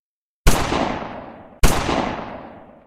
Valter Gun_doubleshot.ogg